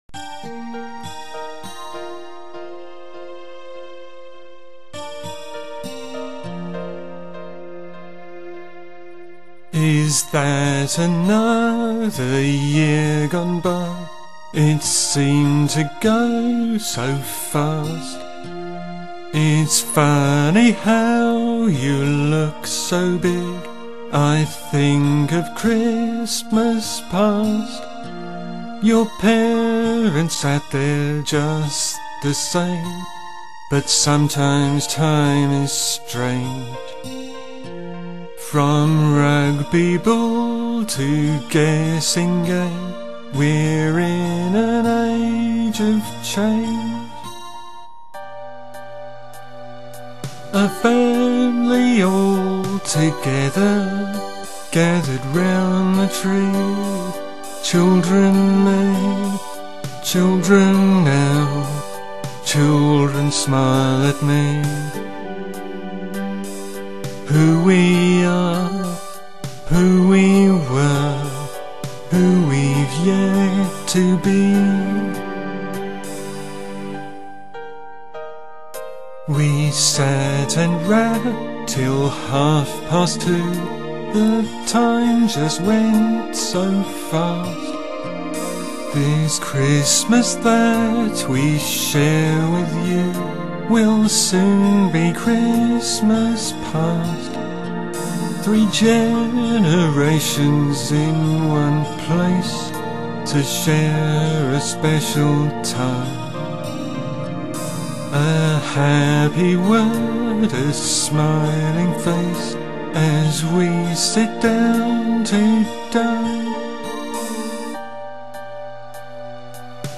This time it was a sentimental song about time passing and children growing up, much more appropriate, so this was the one that went into the video soundtrack, although on the video the ending was cut a lot shorter.